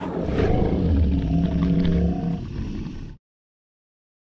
PixelPerfectionCE/assets/minecraft/sounds/mob/guardian/elder_idle1.ogg at mc116
elder_idle1.ogg